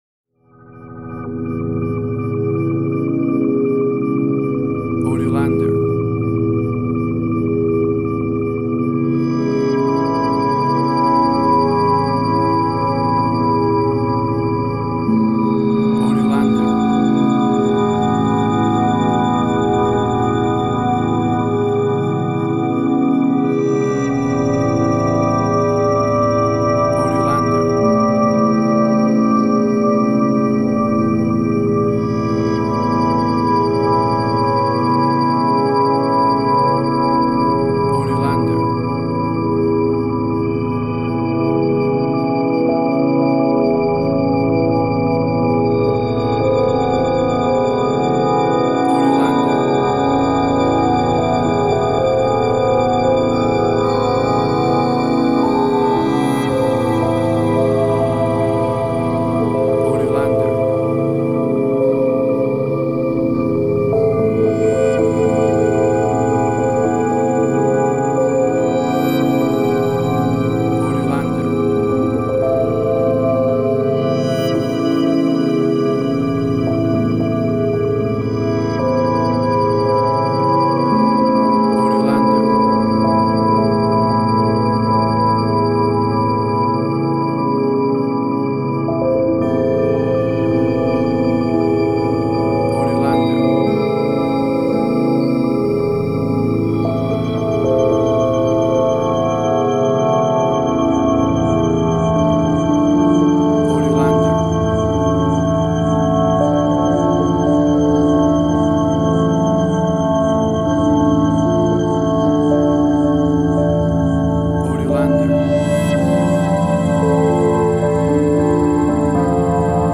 Ambient Strange&Weird